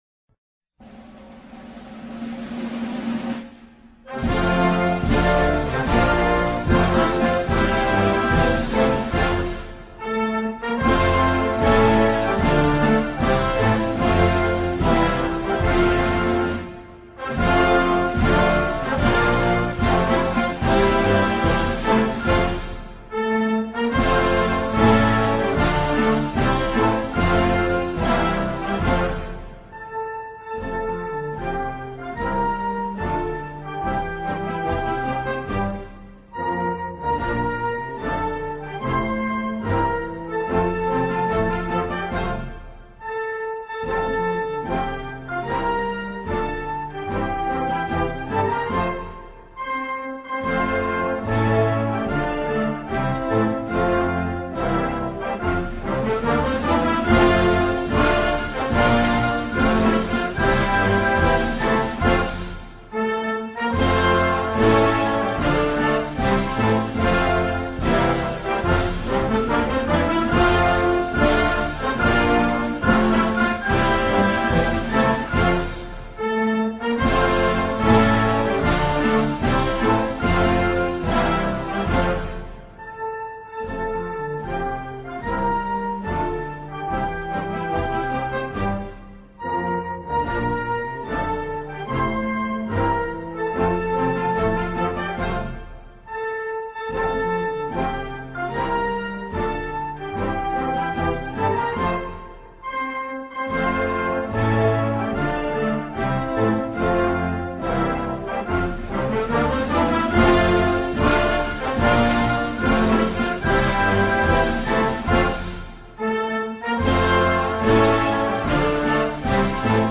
السلام-الوطني-المصري-موسيقي-فقط-.mp3